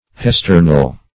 Search Result for " hesternal" : The Collaborative International Dictionary of English v.0.48: Hestern \Hes"tern\, Hesternal \Hes*ter"nal\, a. [L. hesternus; akin to heri yesterday.] Pertaining to yesterday.